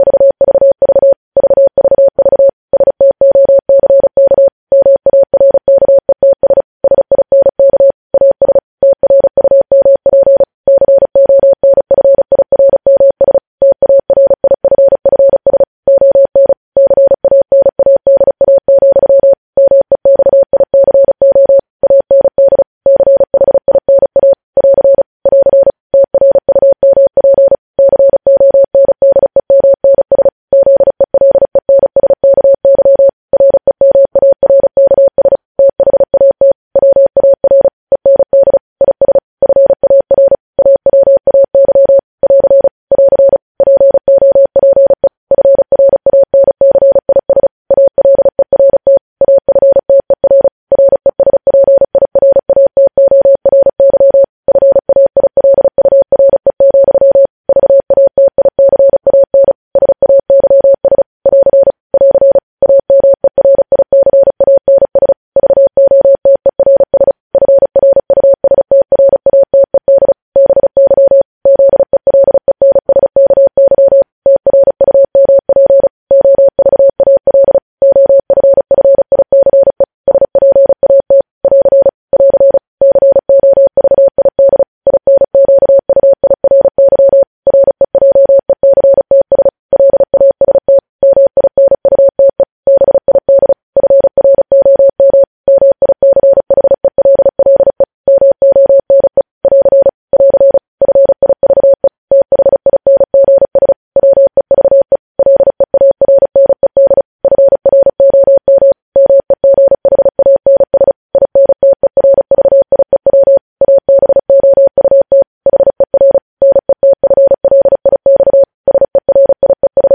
News Headlines at 35 – News Headlines in Morse Code at 35 WPM – Podcast